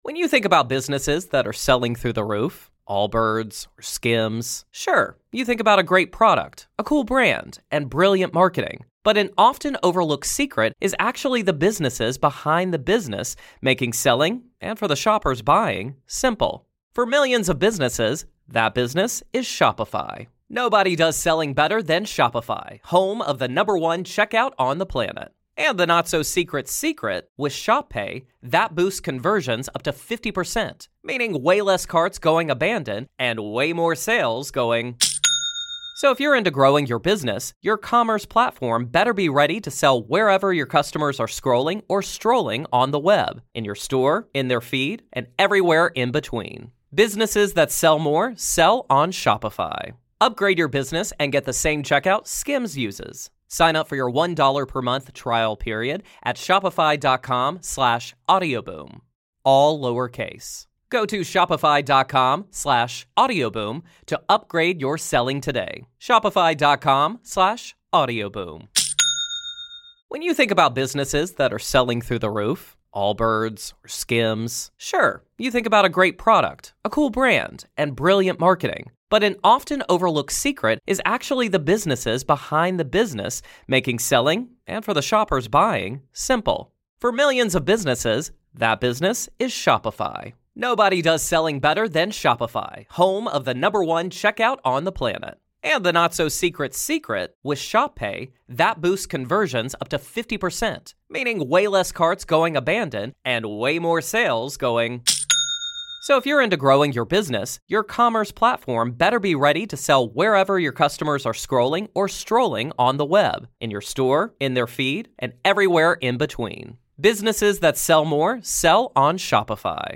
What was fact, and what was fiction? This is Part Two of our conversation.